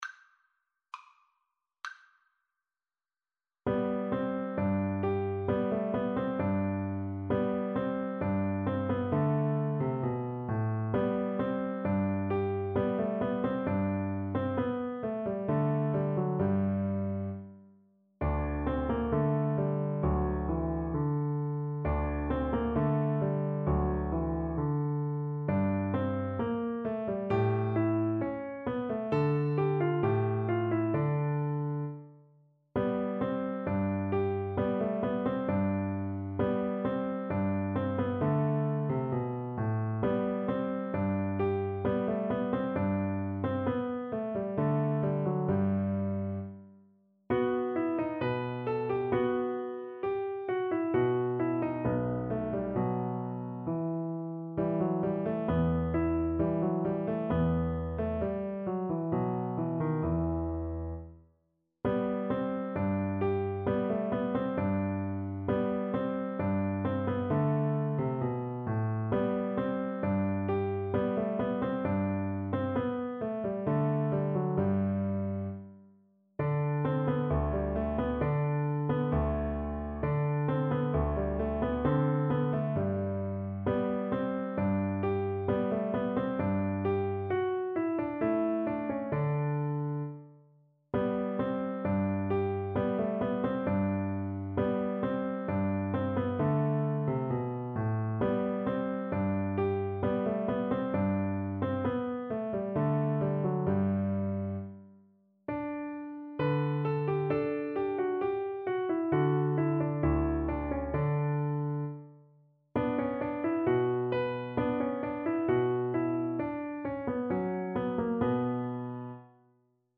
Play (or use space bar on your keyboard) Pause Music Playalong - Piano Accompaniment Playalong Band Accompaniment not yet available transpose reset tempo print settings full screen
Violin
G major (Sounding Pitch) (View more G major Music for Violin )
2/2 (View more 2/2 Music)
~ = 100 Allegretto =c.66
Classical (View more Classical Violin Music)